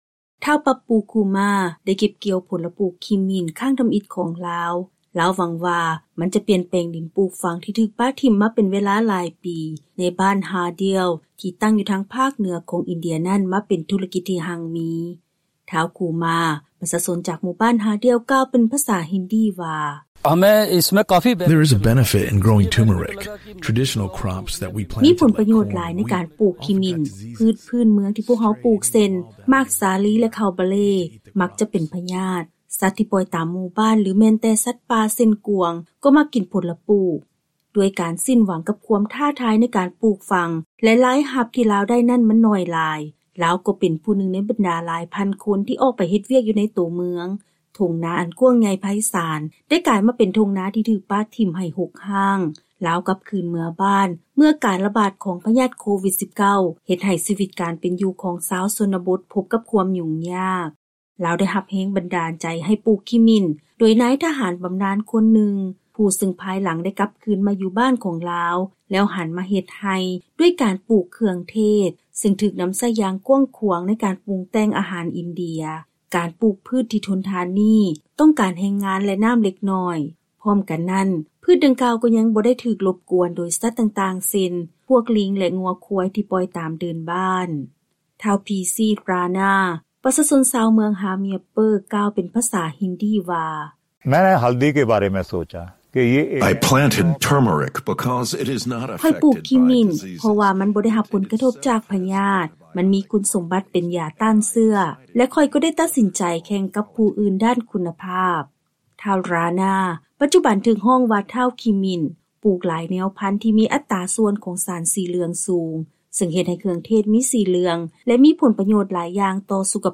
ຟັງລາຍງານກ່ຽວກັບ ທາງເລືອກໃນການປູກຝັງ ຊ່ວຍໃຫ້ຜູ້ຍົກຍ້າຍຖິ່ນຖານ ຟື້ນຟູຊີວິດການເປັນຢູ່ ຢູ່ໃນຂົງເຂດຊົນນະບົດຂອງ ອິນເດຍ